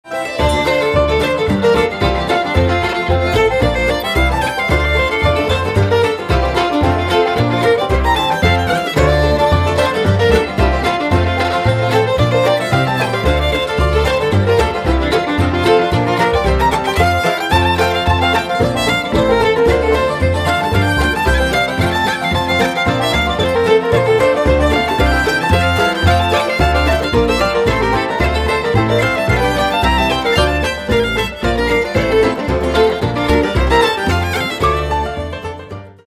Her fiddle sound sparkles, and the overall effect is fresh.